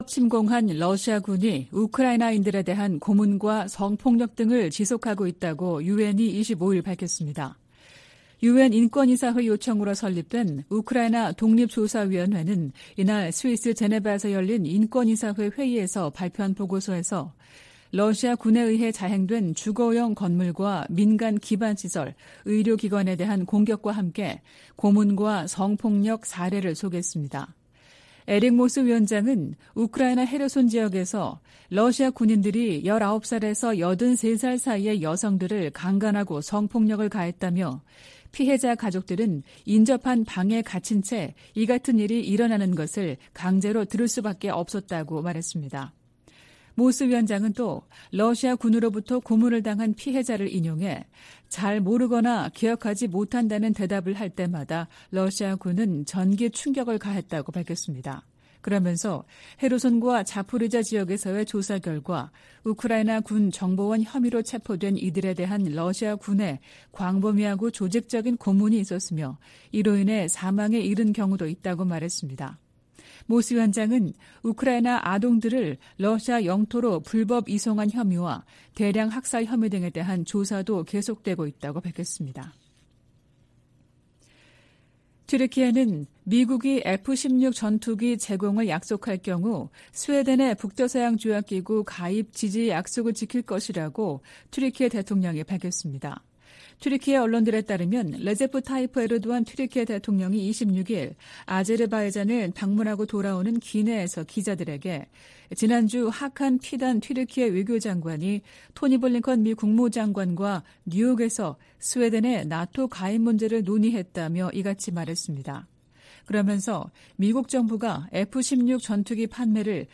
VOA 한국어 '출발 뉴스 쇼', 2023년 9월 27일 방송입니다. 토니 블링컨 미 국무장관은 미한 동맹이 안보 동맹에서 필수 글로벌 파트너십으로 성장했다고 평가했습니다. 한국과 중국, 일본의 외교당국은 3국 정상회의를 빠른 시기에 개최하기로 의견을 모았습니다.